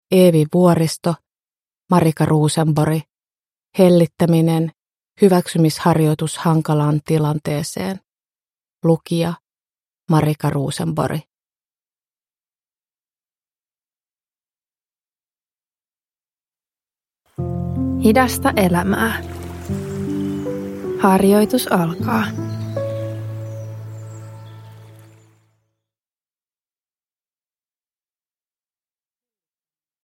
Rauhoittava harjoitus tunnetyöskentelyn avuksi